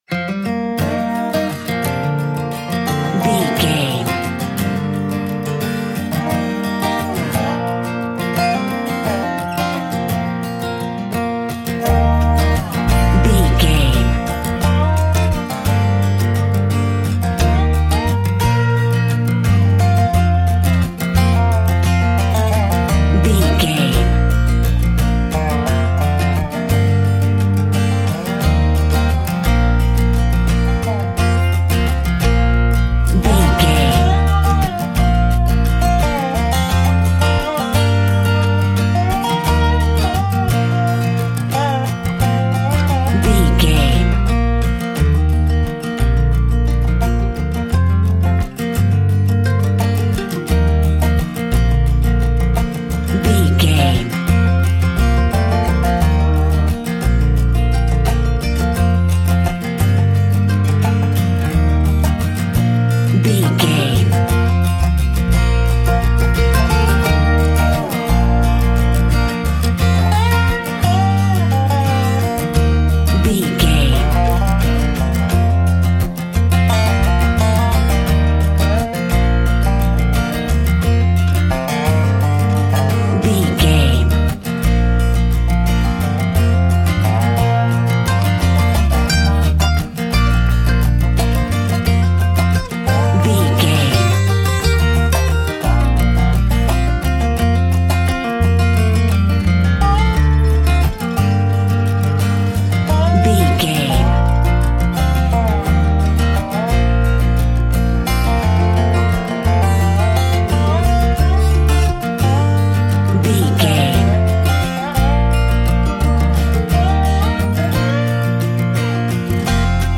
Ionian/Major
B♭
acoustic guitar
bass guitar
banjo